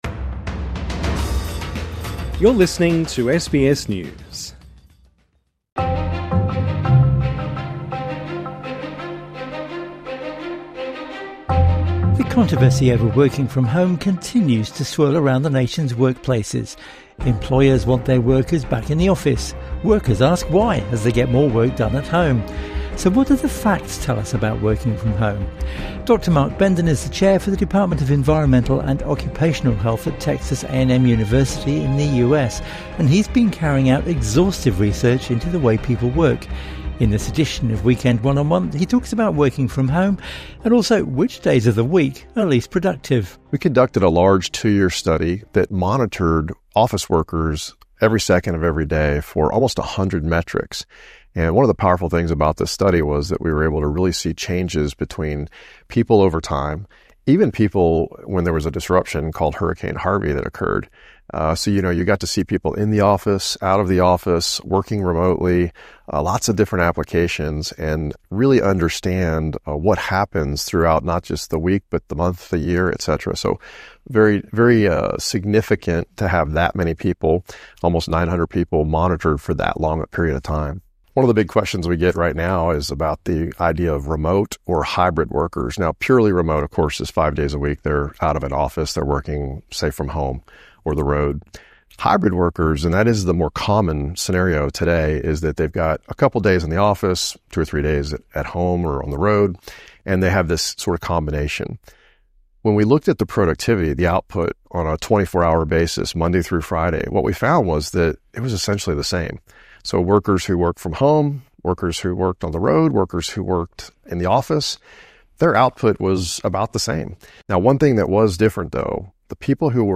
INTERVIEW: The truth about working from home - and should we work on Friday afternoons?